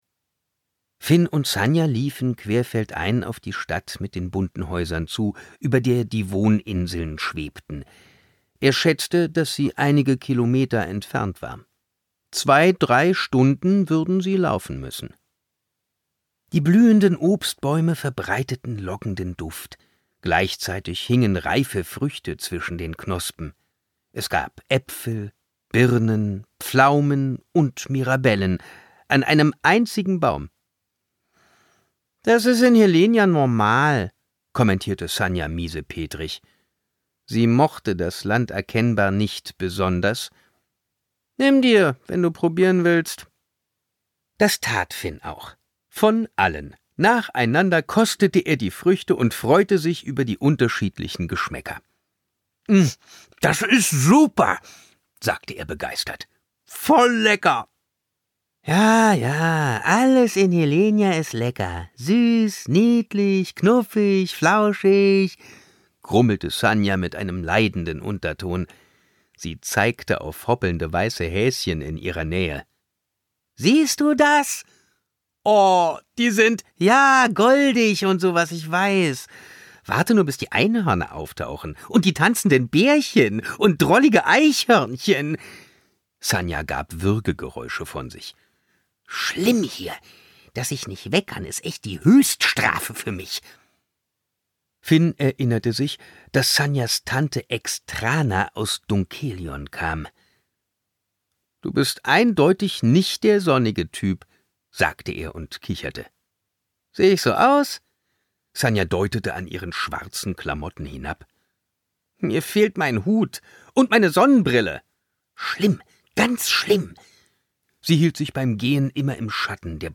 2025 Argon Hörbuch